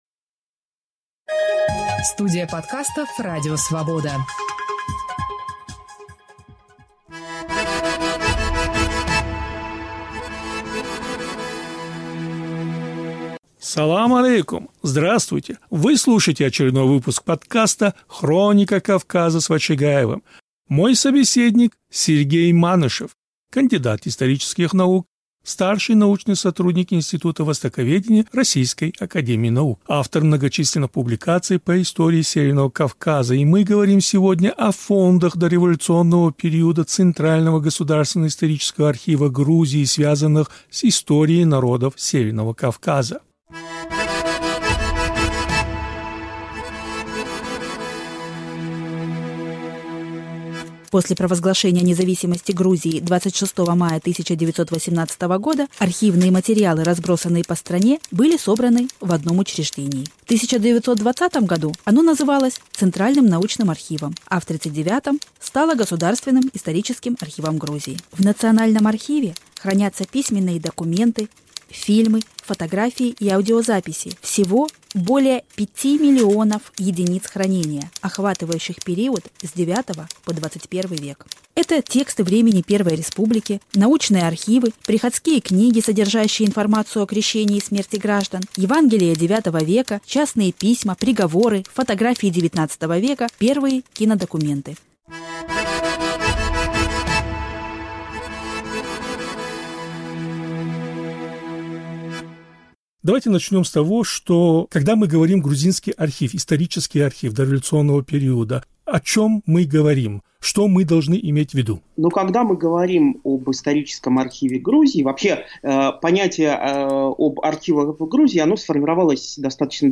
беседует с историком